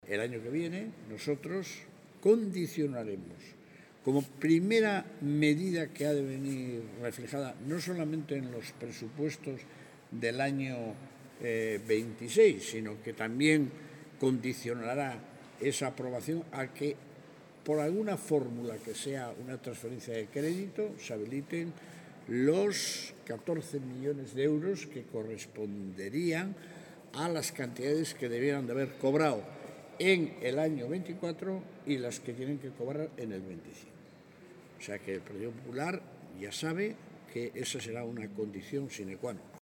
En una comparecencia posterior ante los medios de comunicación, el líder regionalista ha reafirmado el compromiso de los regionalistas con la educación y su respaldo a la reivindicación retributiva del profesorado, que supone un incremento de 325 euros en varias anualidades.